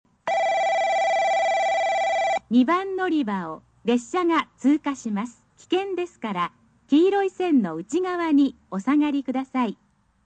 スピーカー：カンノ製作所
音質：D+
2002年冬に登場した新放送
２番のりば 通過放送・女性 （上り・博多方面） (50KB/10秒) 「ベル･･･」　　　　　２番のりばを列車が通過。